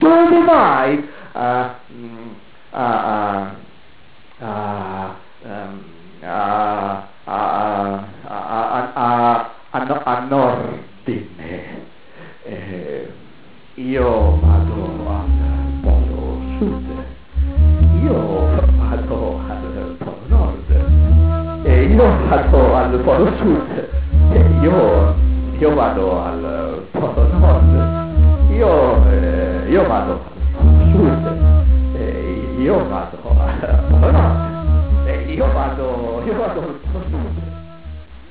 contrabbasso
flauto
un tentativo di introdurre il contrasto in poesia sonora